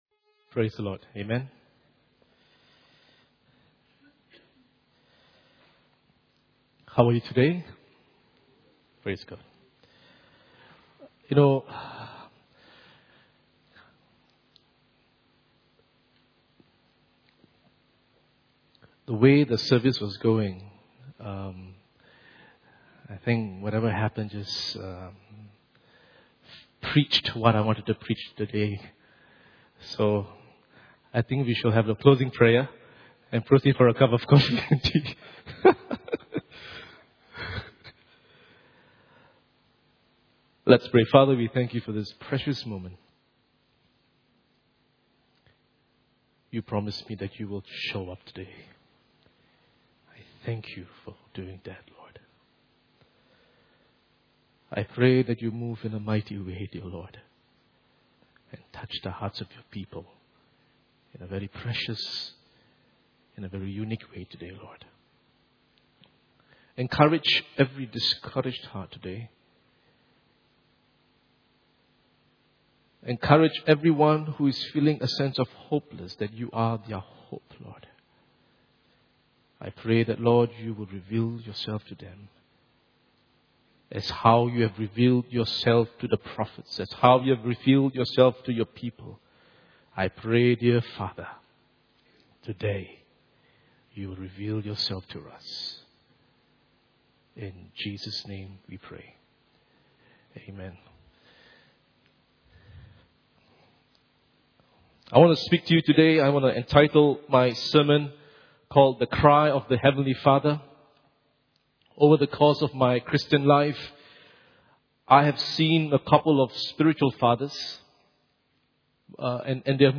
Inhouse Service Type: Sunday Morning « Holiness and Fear of God pt 11